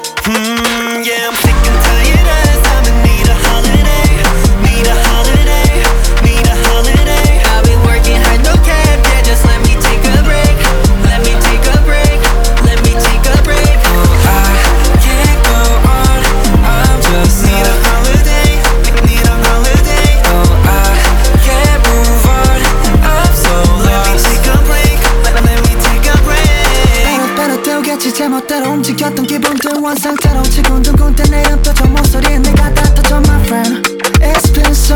Жанр: Поп музыка
K-Pop